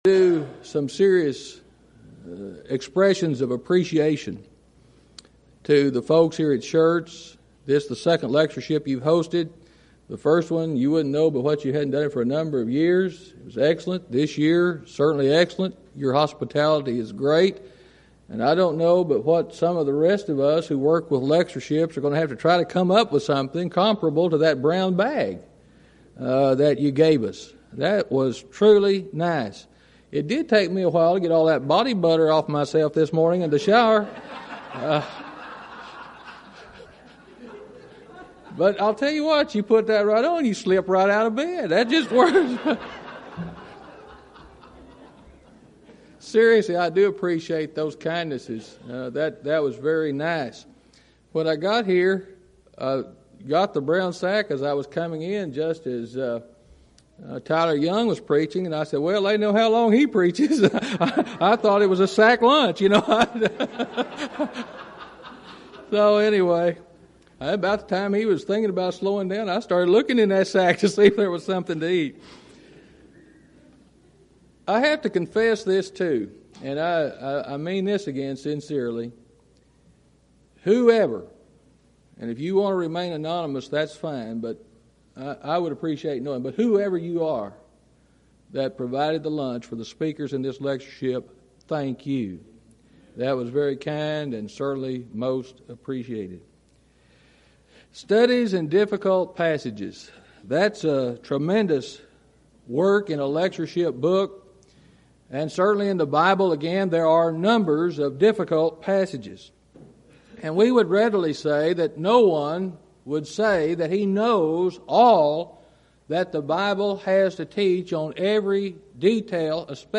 Event: 2nd Annual Schertz Lectures Theme/Title: Studies In Exodus
lecture